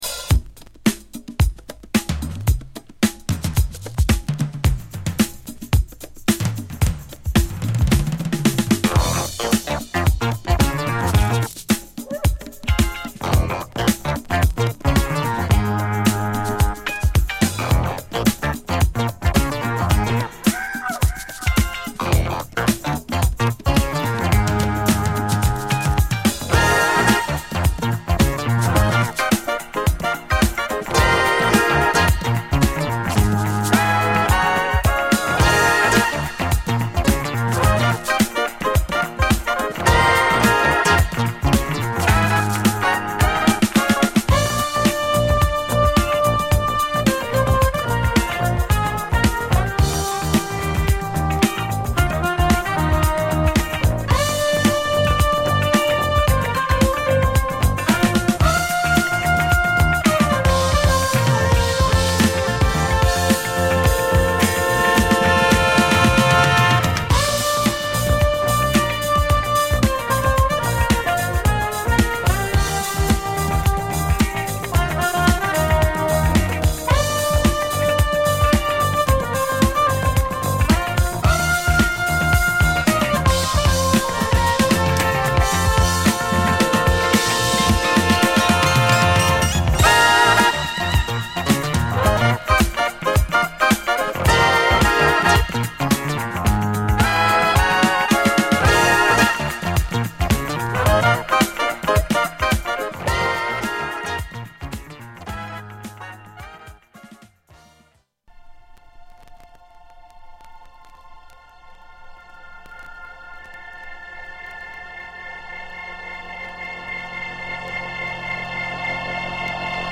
中盤〜後半でもドラムブレイクが飛び出す、カッコ良いコズミックな1曲！